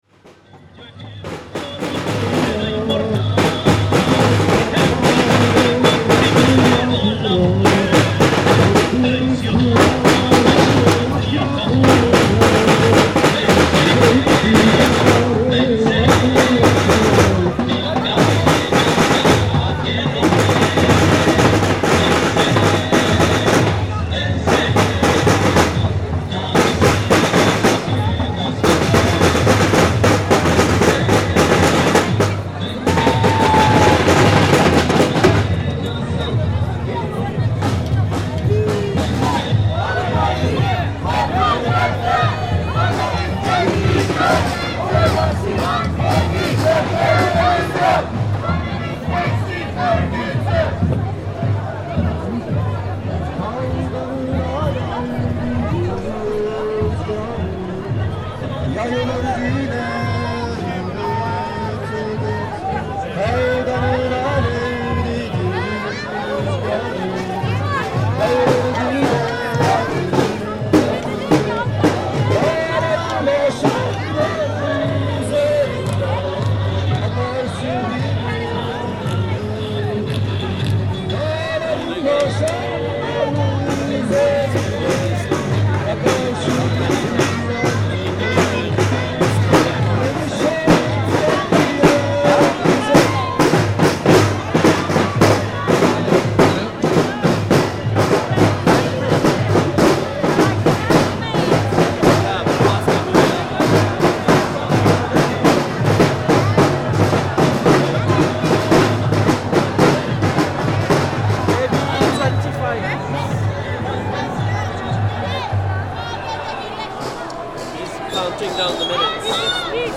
Tags: Rallies and demonstrations Rallies and demonstrations clips UK London Rallies and demonstrations sounds